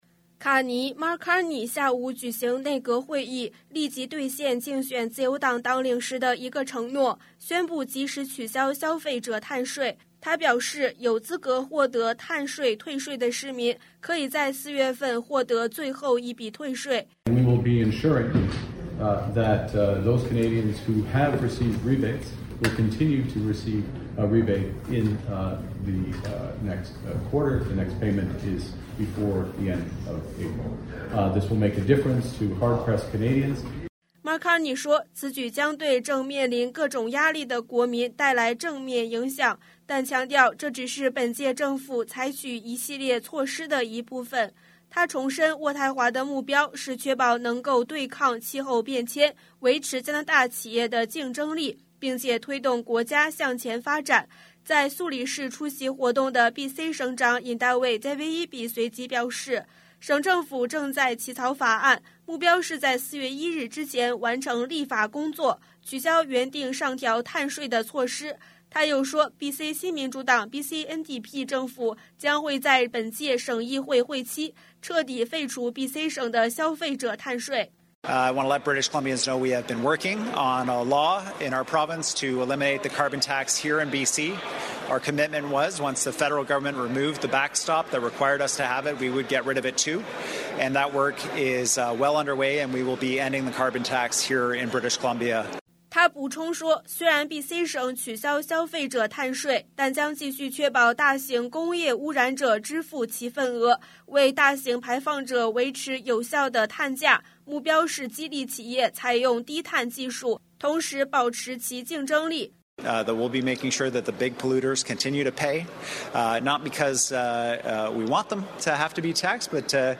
Canada/World News 全國/世界新聞
news_clip_22880_mand.mp3